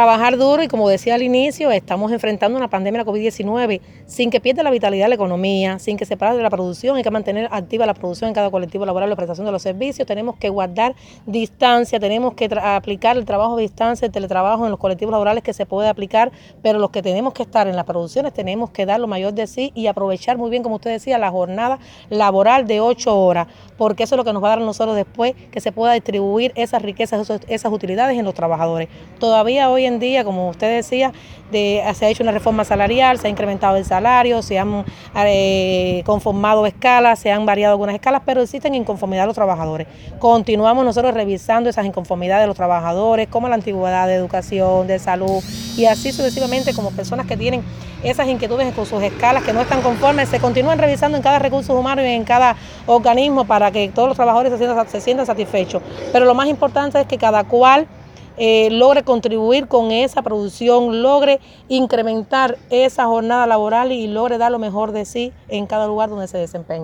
Entrevistas Isla de la Juventud Ordenamiento